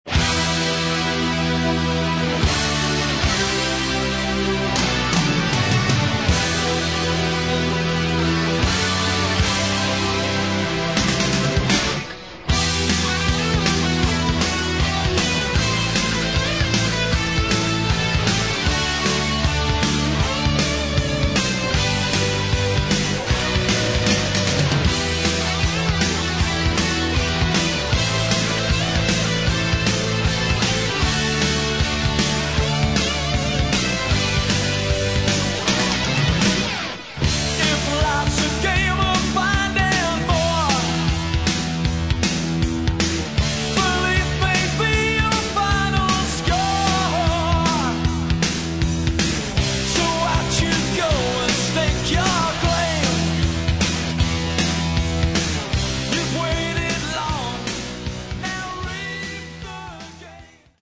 Metal
New Wave Of British Heavy Metal